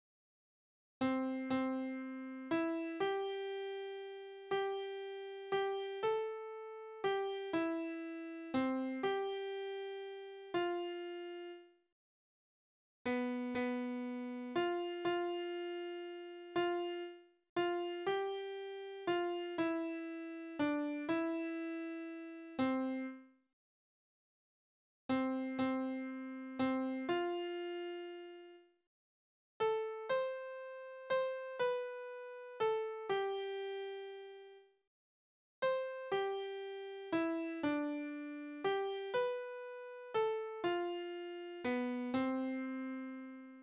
Balladen: Räuber und Mädchen
Tonart: C-Dur
Taktart: 3/4
Tonumfang: Oktave
Besetzung: vokal